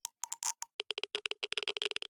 Minecraft Version Minecraft Version latest Latest Release | Latest Snapshot latest / assets / minecraft / sounds / ambient / nether / basalt_deltas / click4.ogg Compare With Compare With Latest Release | Latest Snapshot
click4.ogg